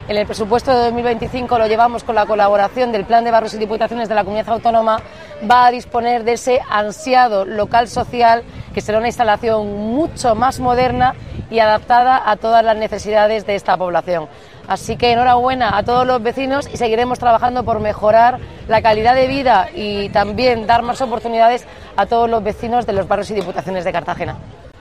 Enlace a Declaraciones de la alcaldesa, Noelia Arroyo, sobre el nuevo local social de La Puebla